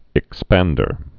(ĭk-spăndər)